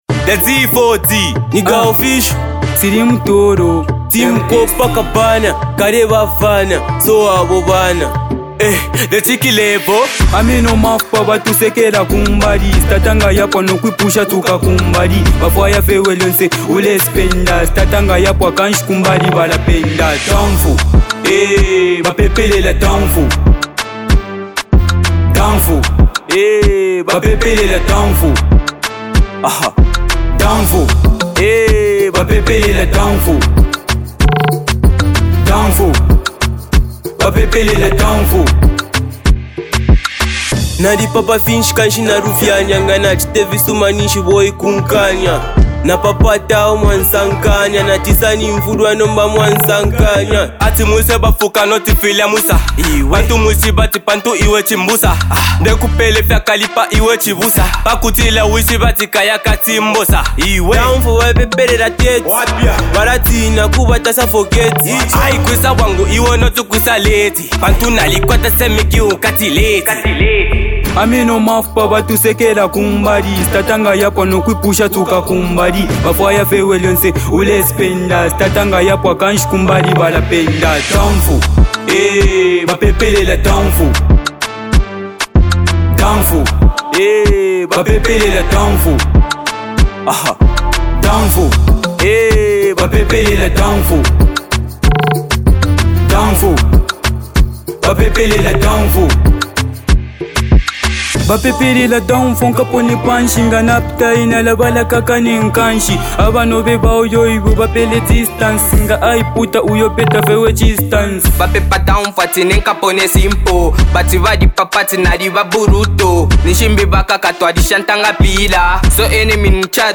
Genre: Afro Pop, Zambia Songs